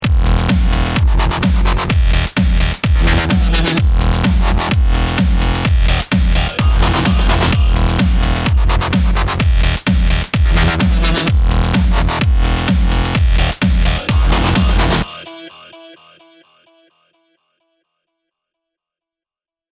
Big Electro House Basses with GTS-39
As you can hear there’s a few different bass sounds in the song.
Big-Electro-House-Basses-with-GTS-39-DRY.mp3